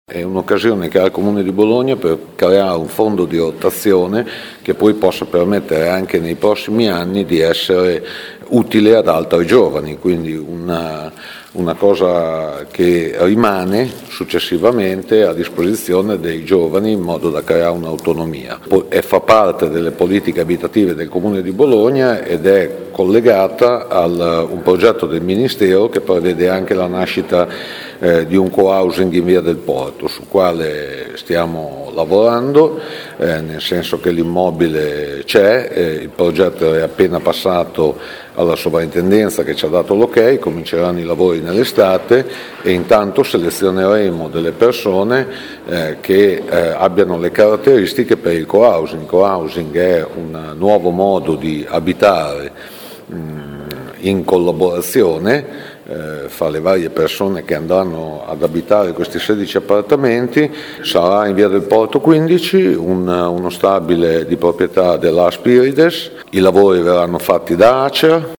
Ascolta l’assessore ai Lavori pubblici Riccardo Malagoli